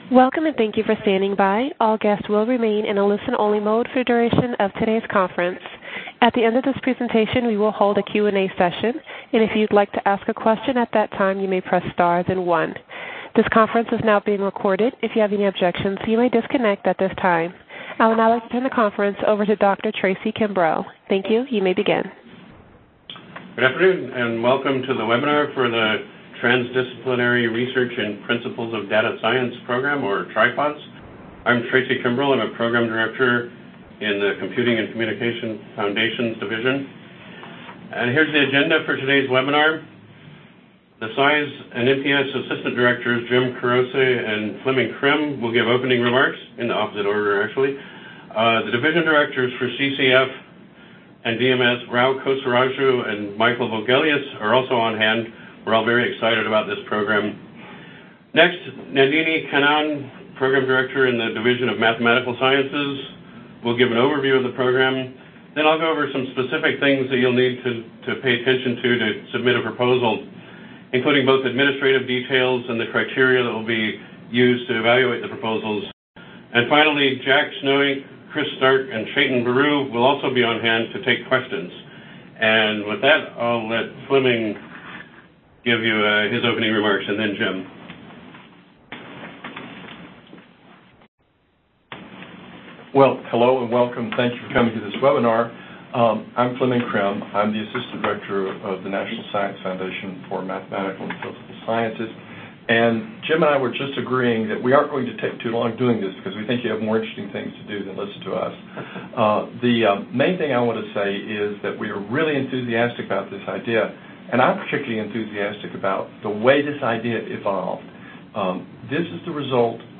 Transdisciplinary Research in Principles of Data Science (TRIPODS) Webinar